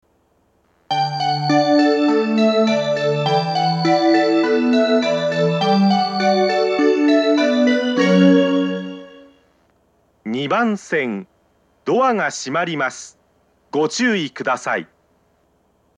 上りは交換がなければ余韻までは鳴りやすいですが、下りは交換がなくても途中切りが多いです。
２０１０年３月以前に放送装置を更新し、発車メロディーに低音ノイズが被るようになりました。
熱海・東京方面   ２番線接近放送
２番線発車メロディー